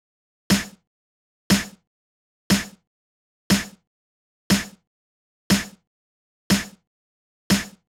23 Snare.wav